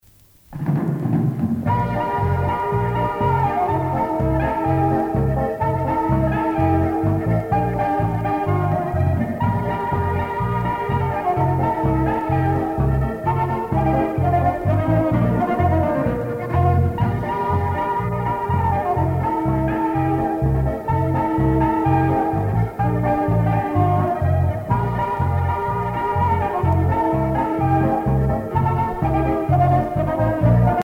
danse : marche
Orchestre de variétés
Pièce musicale éditée